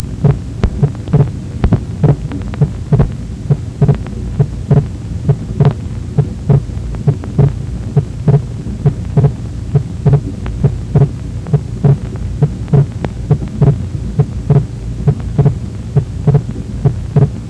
เสียงหัวใจ (Heart sound)
Wide physiologic splitting  มีการแยกห่างของเสียง A2 และ P2ทั้งในช่วง
หายใจเข้าและหายใจออก แต่ระยะเวลาที่เสียงทั้งสองแยกจากกันยังคงเปลี่ยน